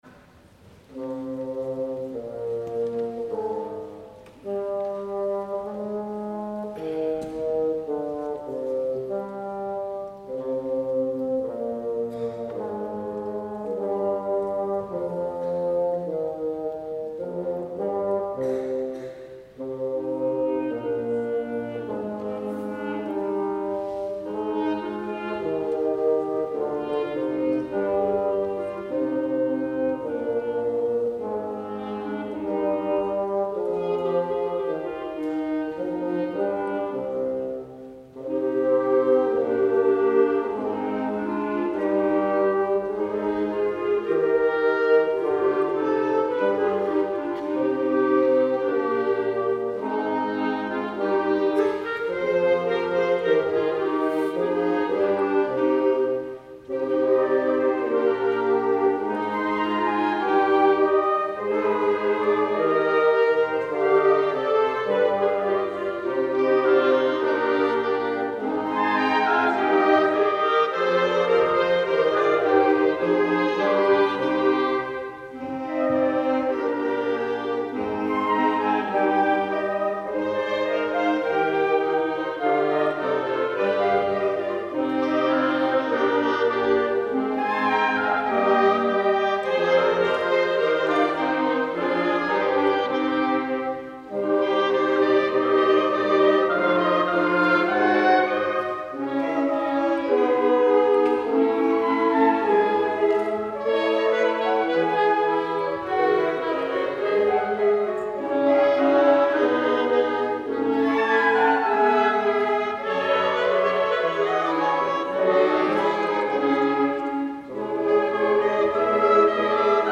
Musik für schulisches Holzbläser-Ensemble
Holzbläsermusik über eine Einsingübung